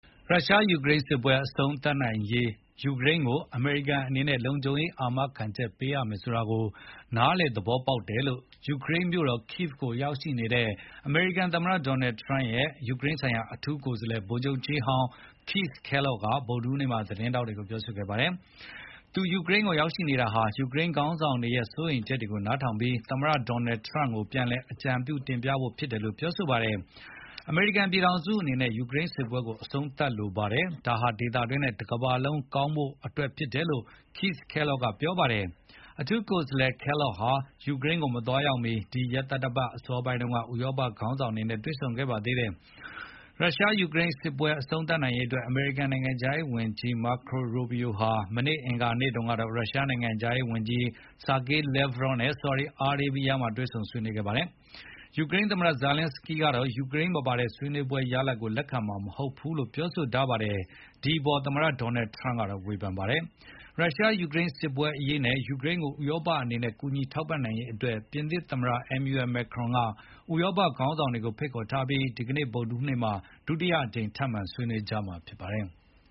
ရုရှား-ယူကရိန်းစစ်ပွဲအဆုံးသတ်နိုင်ရေး ယူကရိန်းကိုအမေရိကန်အနေနဲ့ လုံခြုံရေးအာမခံချက် ပေးရမယ်ဆိုတာကိုနား လည်သဘောပေါက်တယ်လို့ ယူကရိန်းမြို့တော် Kyiv ကိုရောက်ရှိနေတဲ့ အမေရိကန်သမ္မတရဲ့ယူကရိန်းဆိုင်ရာ အထူးကိုယ်စားလှယ် ဗိုလ်ချုပ်ကြီးဟောင်း Keith Kellogg က ဗုဒ္ဓဟူးနေ့သတင်းစာရှင်းလင်းပွဲမှာပြောဆိုခဲ့ပါတယ်။